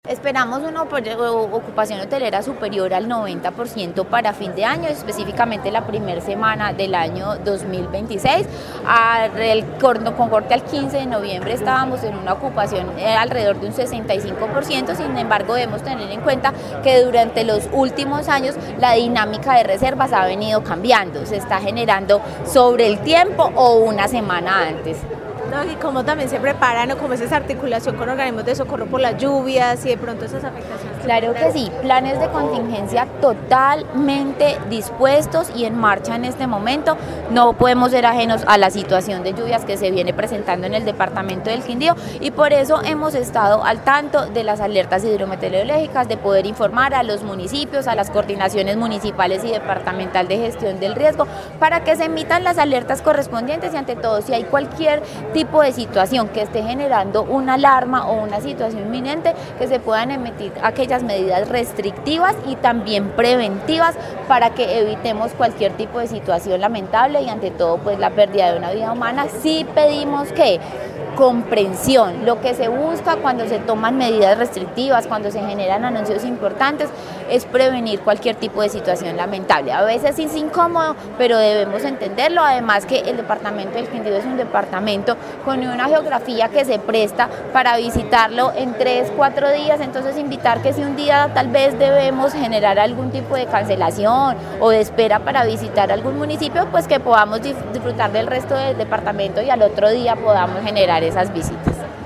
Secretaria de turismo del Quindío sobre temporada fin de año